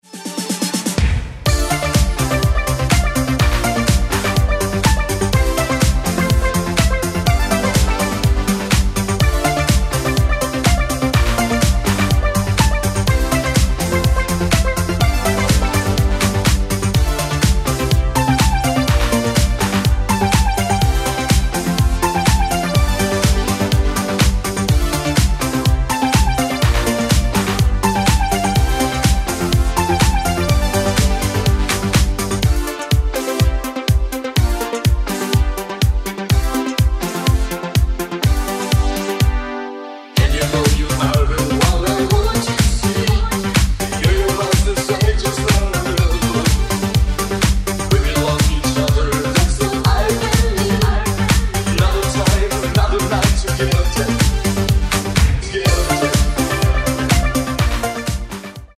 Reissue of this italo disco rarity from 1988.